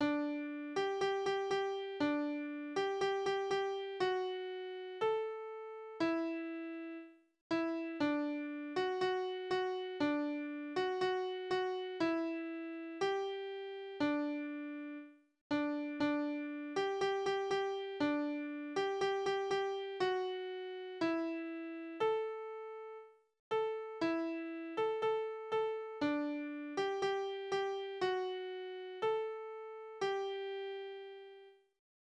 Kinderlieder: Bub und Spinne
Tonart: G-Dur
Taktart: C (4/4)
Tonumfang: Quinte
Besetzung: vokal